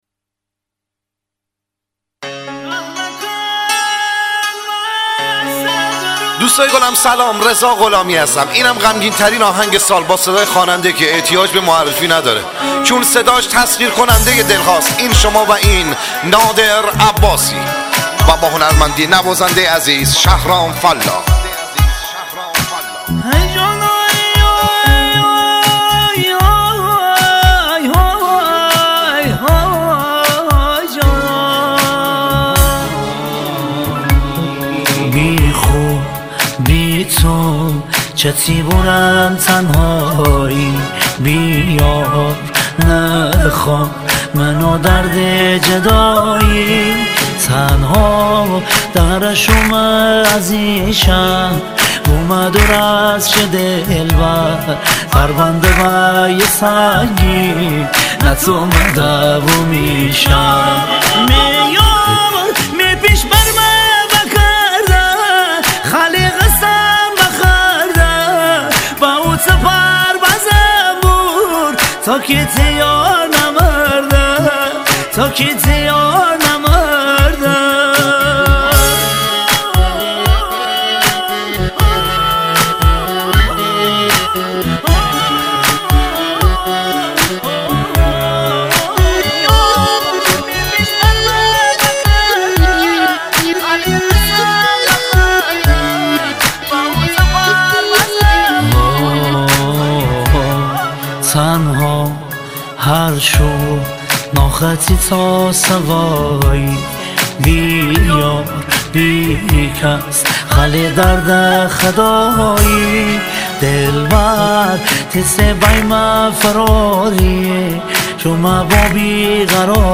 آهنگ شمالی
آهنگ گیلانی
آهنگ های شاد شمالی